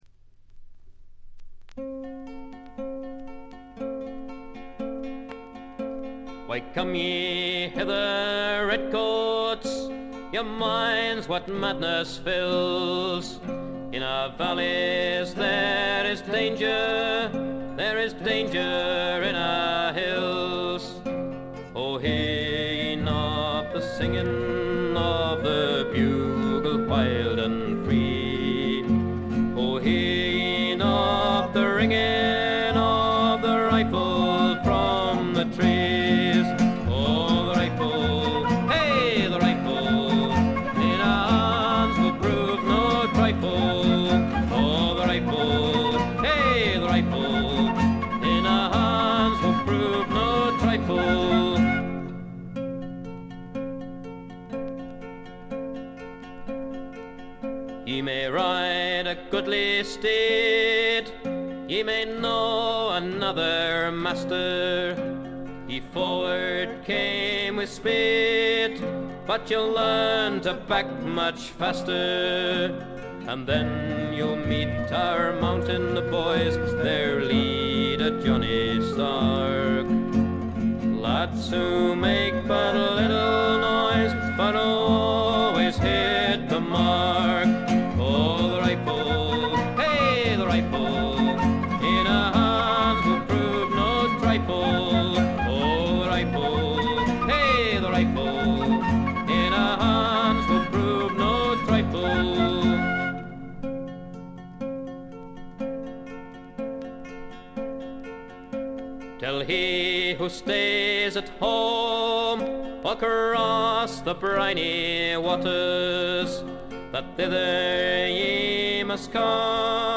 静音部でチリプチ少々。ところどころで散発的なプツ音。
試聴曲は現品からの取り込み音源です。
Guitar, Lead Vocals
Guitar, Banjo, Mandolin, Vocals
Flute, Vocals